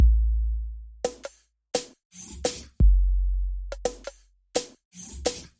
沉重的地动山摇
描述：非常重的霹雳鼓和贝司的170bpm
标签： 低音 节拍 压缩模式SED DNB hardstep
声道立体声